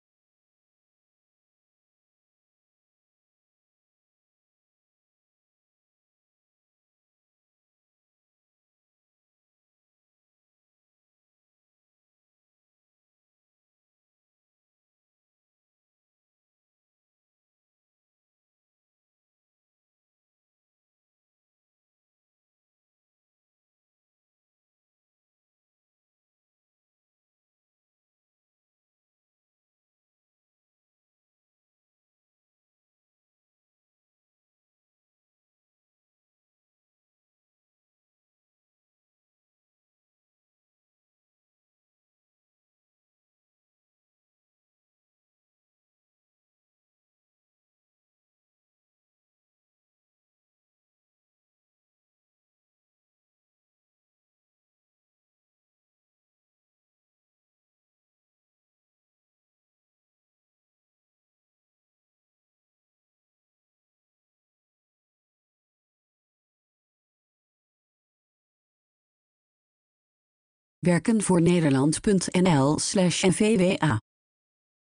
Ze beantwoorden de vragen van een interviewer buiten beeld en praten met veel enthousiasme over hun werk.
Jurist Bezwaar & Beroep Op de achtergrond zijn de hele tijd cafégeluiden te horen.